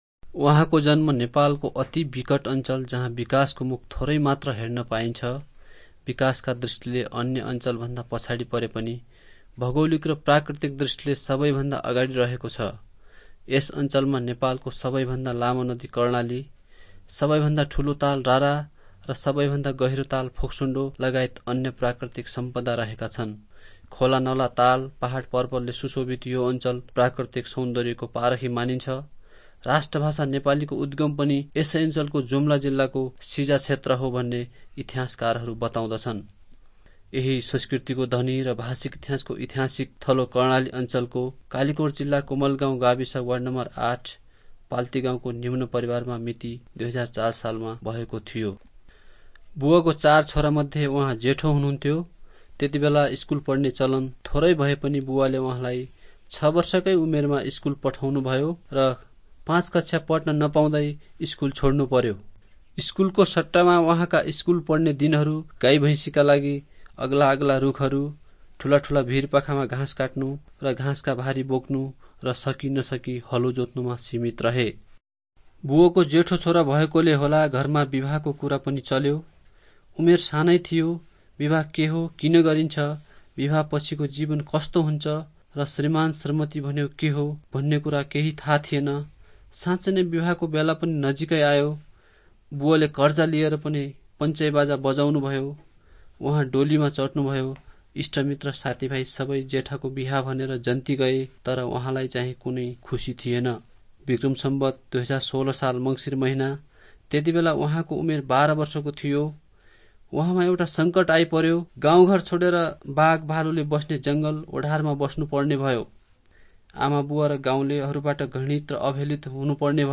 Testimony Doteli - Nepali Christian Resources and Media